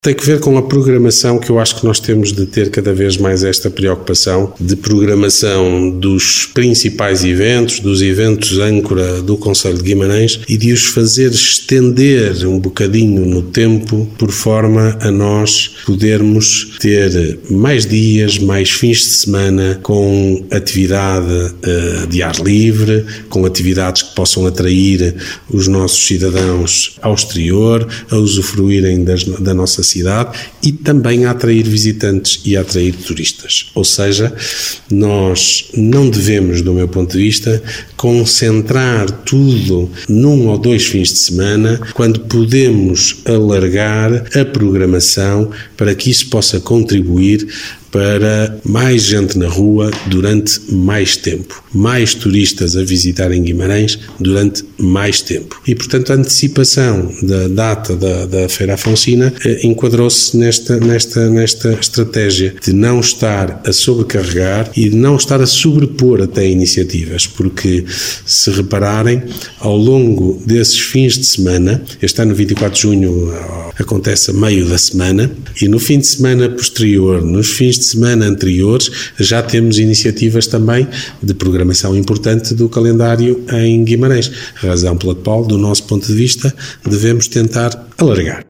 Declarações de Ricardo Araújo, presidente da Câmara Municipal de Guimarães.